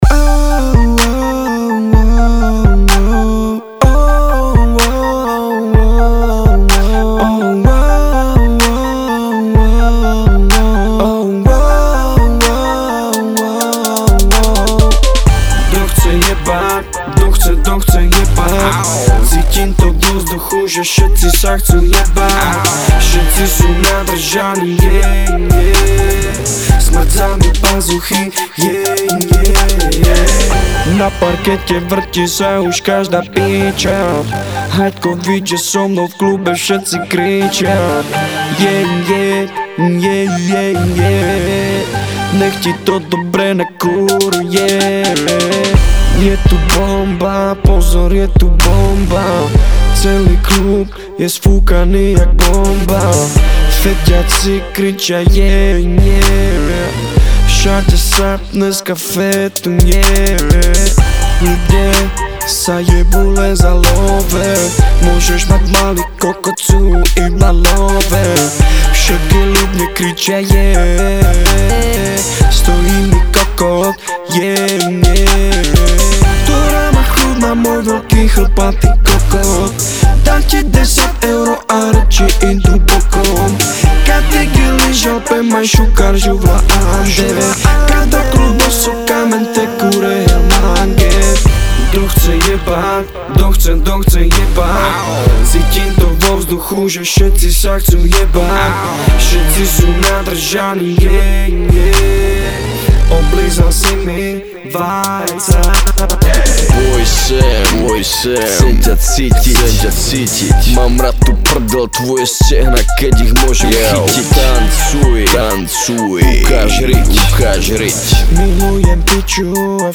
Styl: Hip-Hop Rok